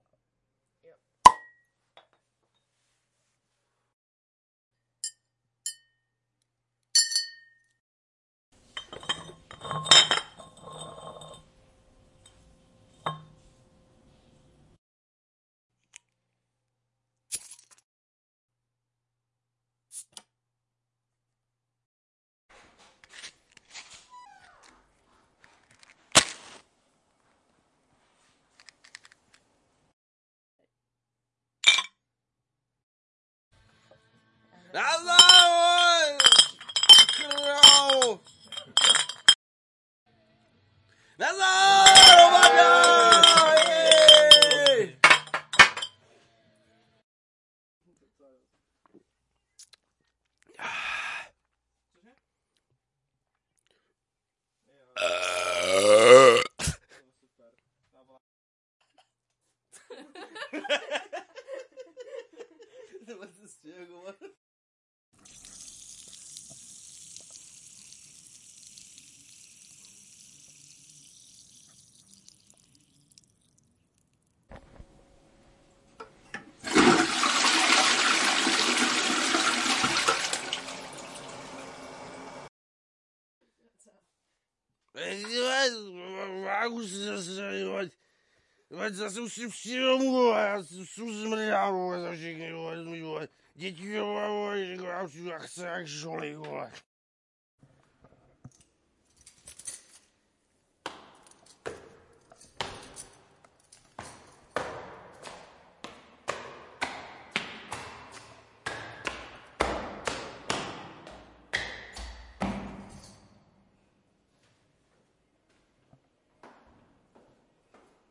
描述：1.用打火机打开一瓶啤酒2。开瓶器打开一瓶啤酒3。用打火机打开啤酒 仔细4。空啤酒瓶tinkling5。空啤酒瓶滴6。盒子里的火柴声，点亮它们7。两个人叮当响啤酒瓶8。一群人叮当响啤酒瓶9。一群人用啤酒瓶叮当作响10。喝酒和打嗝11。 laughter12。 toilet13。喝醉了的掷骰子14。回家喝醉了
Tag: cheerfulpeople 酒馆 啤酒